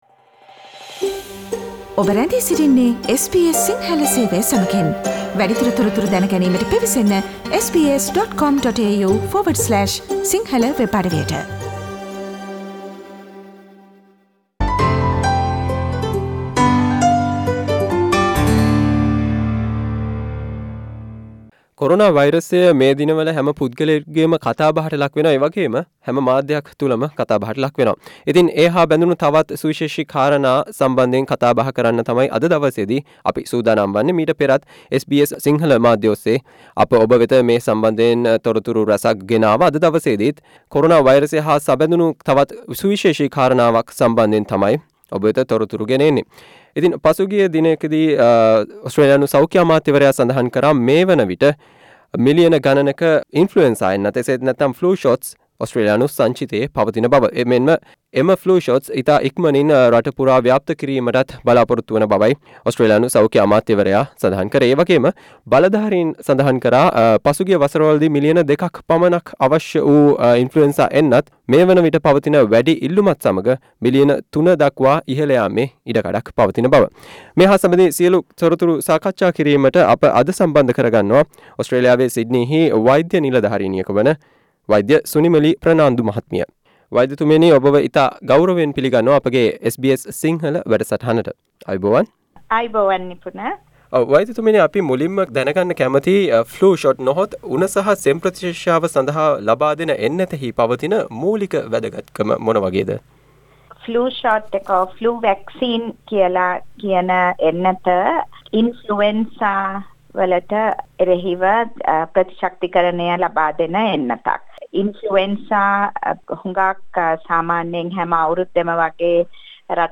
SBS සිංහල ගුවන්විදුලිය සිදු කල සාකච්චාව